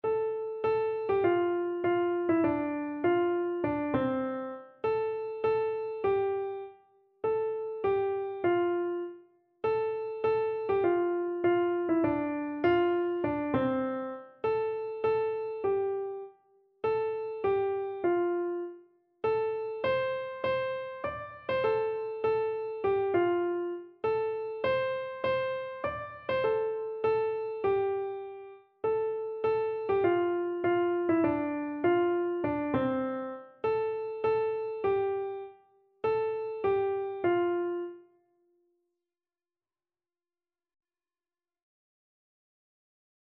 Free Sheet music for Keyboard (Melody and Chords)
4/4 (View more 4/4 Music)
Keyboard  (View more Easy Keyboard Music)
Classical (View more Classical Keyboard Music)